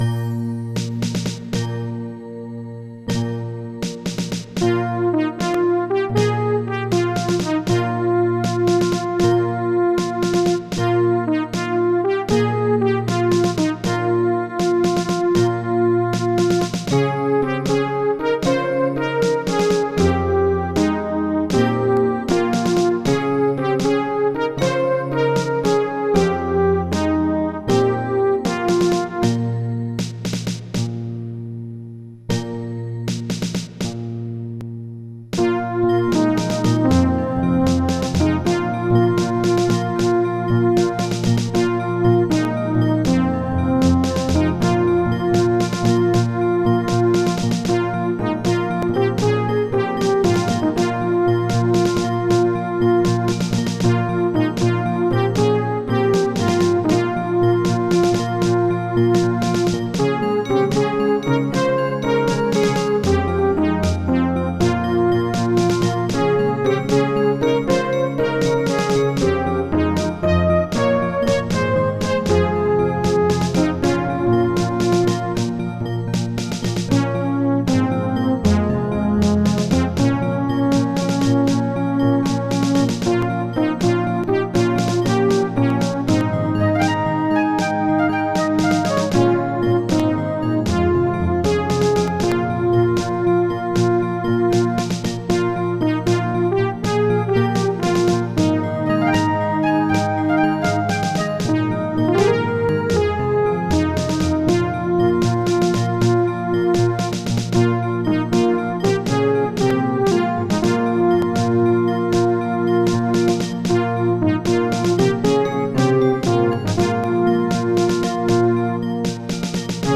Roland LAPC-I
* Some records contain clicks.